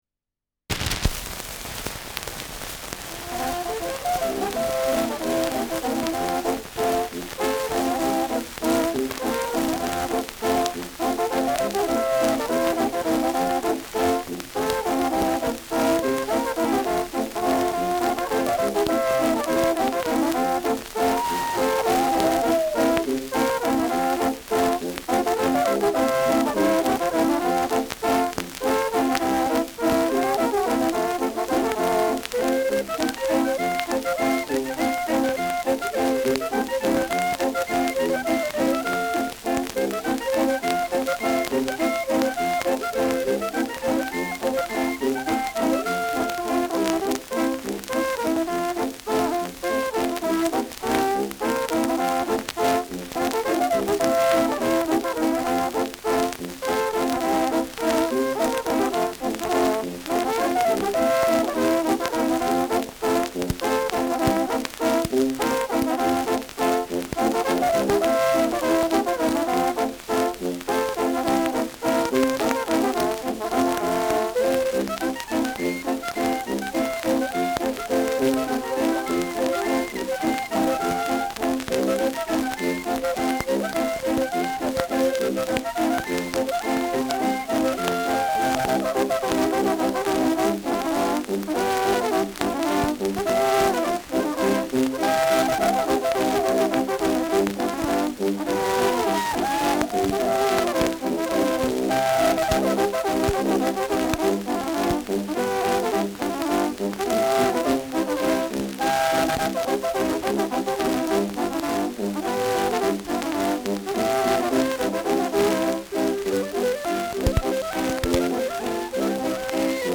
Schellackplatte
Starkes Grundrauschen : Durchgehend leichtes bis stärkeres Knacken : Verzerrt an lauten Stellen